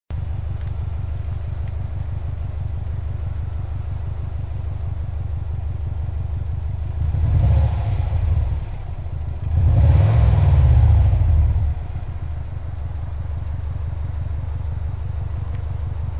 マフラー交換 ― シムス　リヤマフラー
アイドリング時は軽く歯切れの良いとは言えない低音が響きます。
社外品にしてはおとなしい感じのマフラーです。
ボクサーサウンド自体がドコドコと低い音を奏でるだけなんでマフラー替えても上までカチ回したい気分にはならないですねぇ。
2500回転位迄・マフラー近辺で録音 [mp3-300kb]